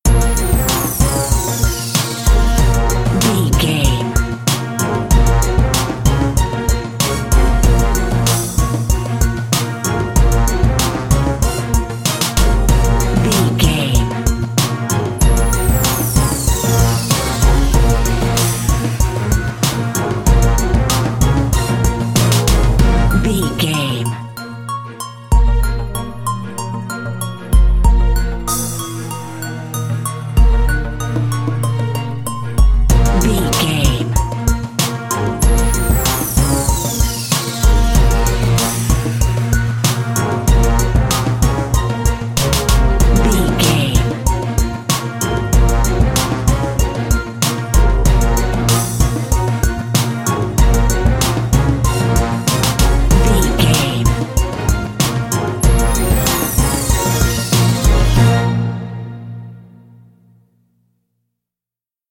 Aeolian/Minor
B♭
drums
percussion
strings
conga
brass
funky